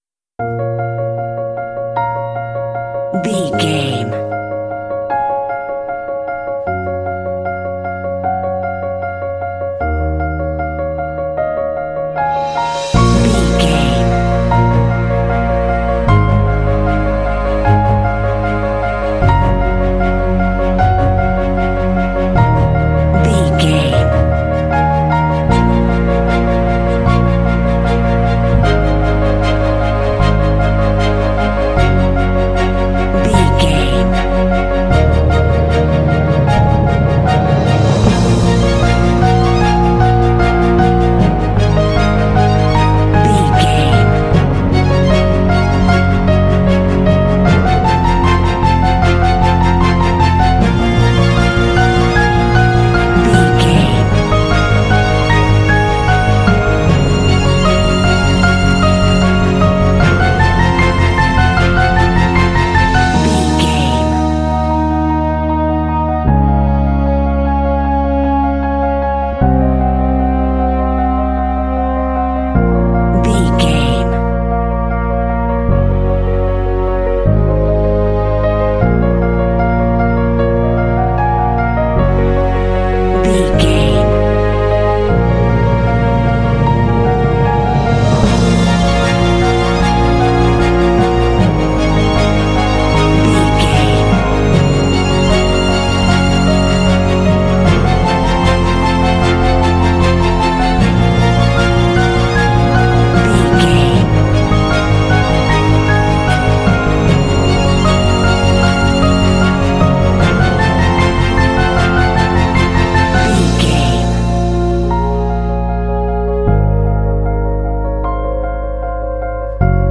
Epic / Action
Fast paced
Aeolian/Minor
B♭
piano
strings
orchestra
cinematic
underscore